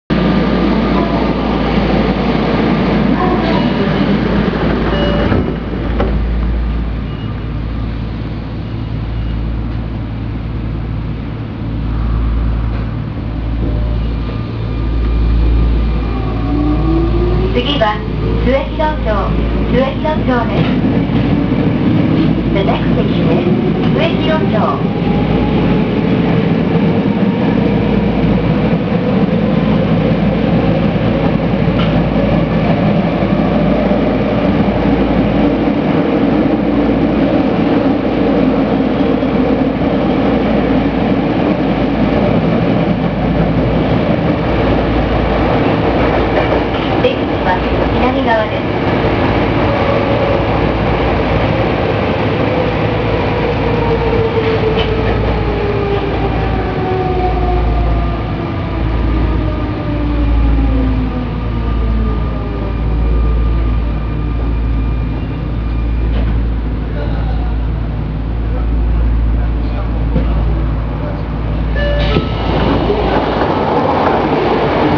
・01系走行音
【銀座線】上野広小路→末広町（1分15秒：408KB）
起動音がきれいな和音になるタイプのチョッパ車は01系から始まりました。01系と02系、03系と05系で和音の響き方がやや異なります。ドアチャイムの音色がほかの車両とやや異なるのが大きな特徴です。